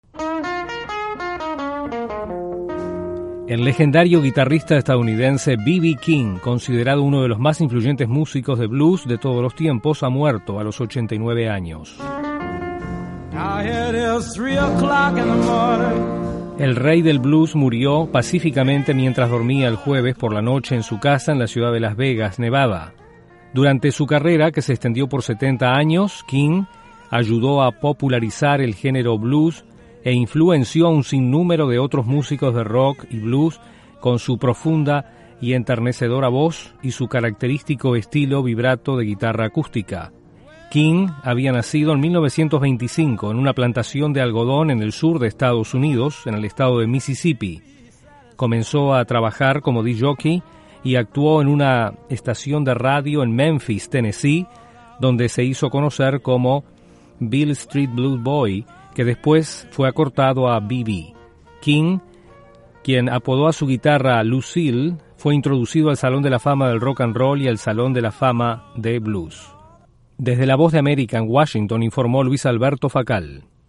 Muere el afamado músico estadounidense de blues B.B. King. Desde la Voz de América en Washington